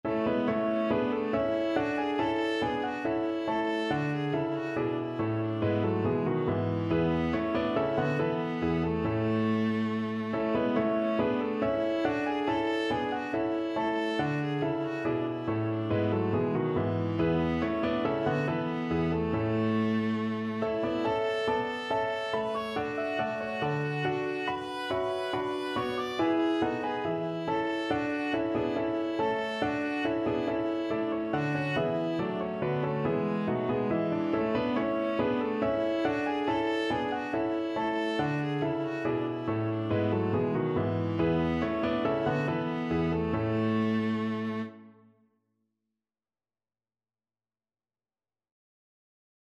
4/4 (View more 4/4 Music)
Classical (View more Classical Viola Music)